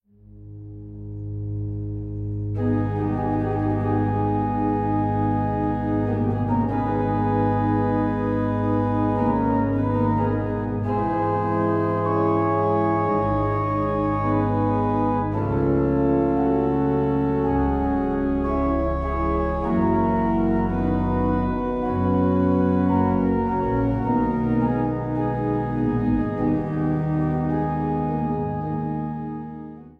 Orgel und Cembalo
Seine Hauptgattung war die Toccata.